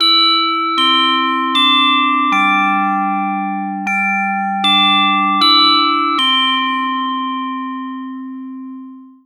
Reloj de salón dando las horas
Westminster
campana
carillón
melodía
reloj
Sonidos: Hogar